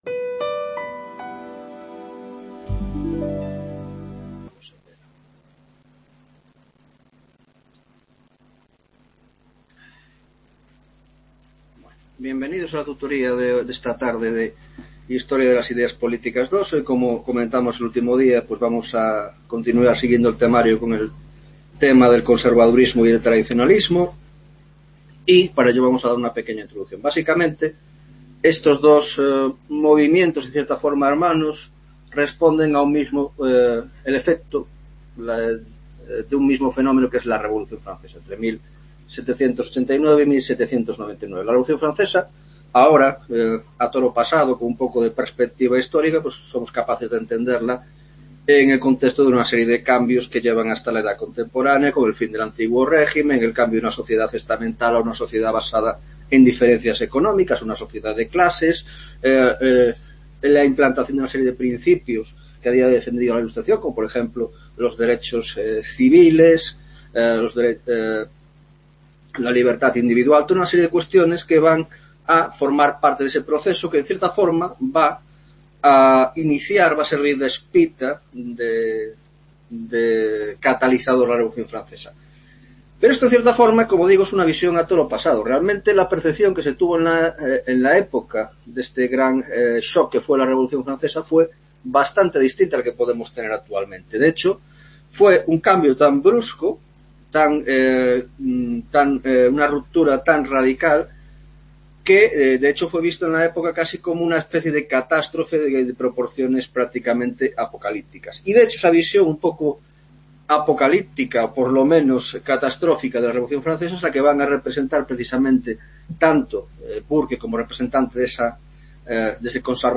7ª Tutoria de Historia de las Ideas Políticas 2 (Grado de Ciencias Políticas)